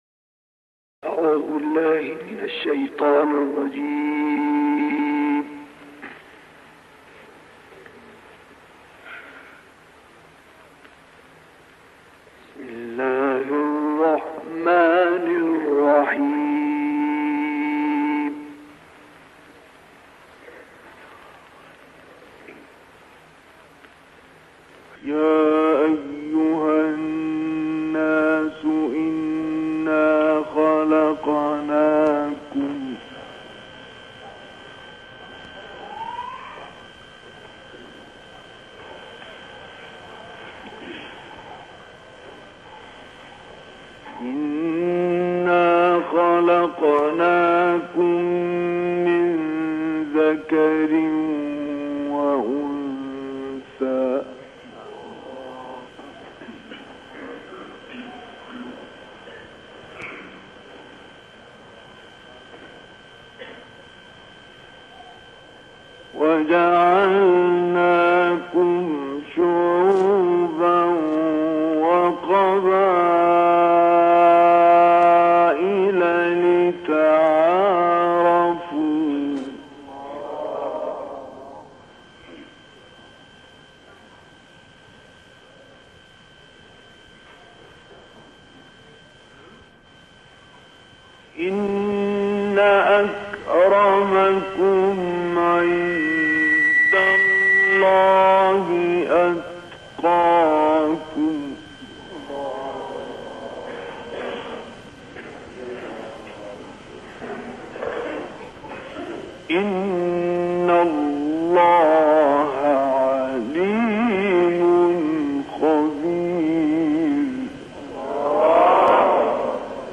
تلاوت آیات سوره های حجرات ، قاف ، انشراح و توحید توسط شیخ مصطفی اسماعیل در سال 1957 در مسجد جامع شیخ المغربی در سوریه.